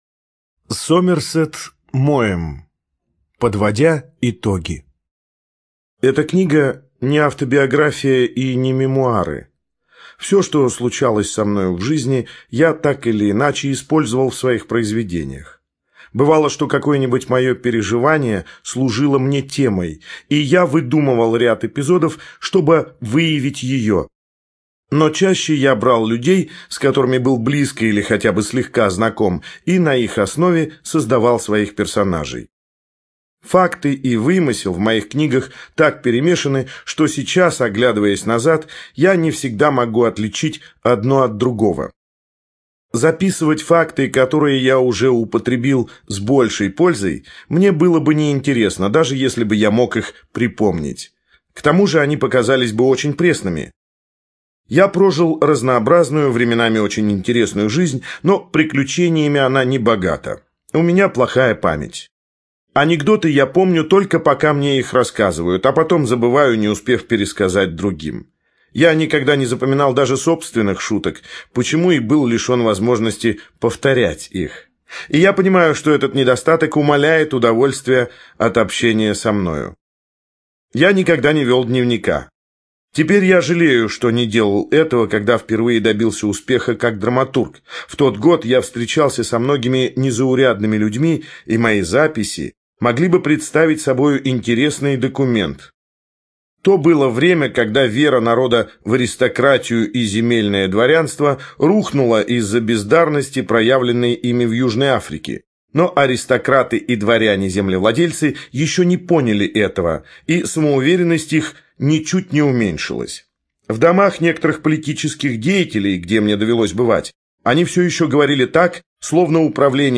Студия звукозаписиБиблиофоника